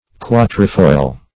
Search Result for " quatrefoil" : The Collaborative International Dictionary of English v.0.48: Quatrefeuille \Qua"tre*feuille\, Quatrefoil \Qua"tre*foil\, n. [F. quatre feuilles.]
quatrefoil.mp3